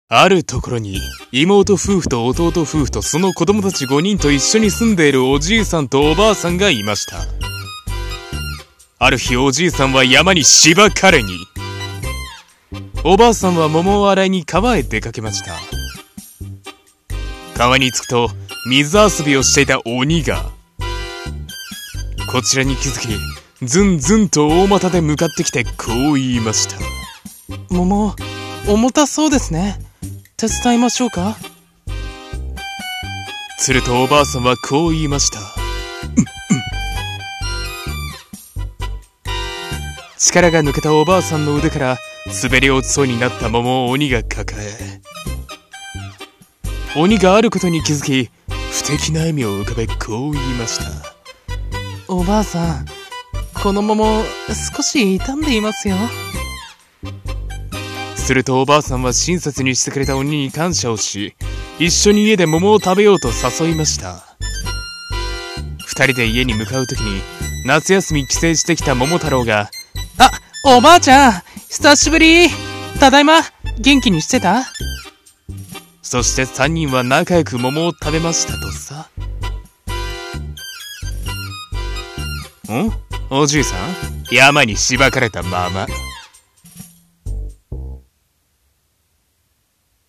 【ギャグ声劇】日本昔話【桃太郎編】